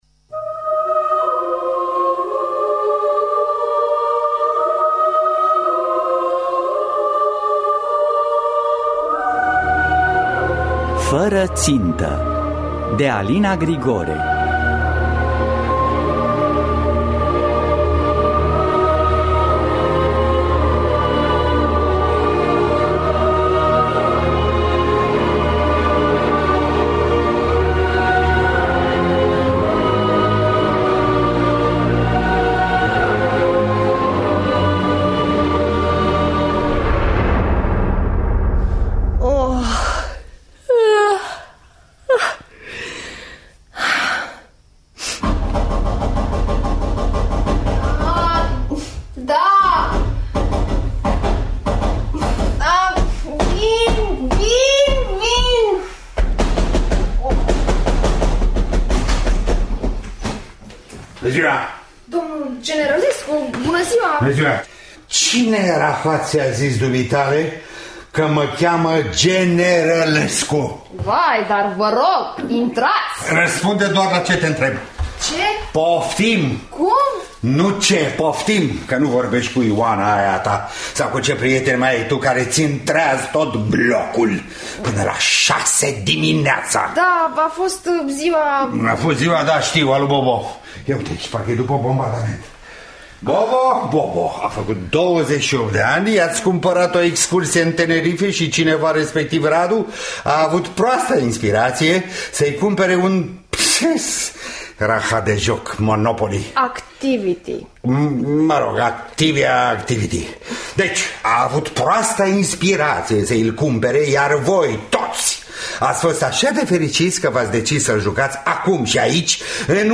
Fără țintă de Alina Grigore – Teatru Radiofonic Online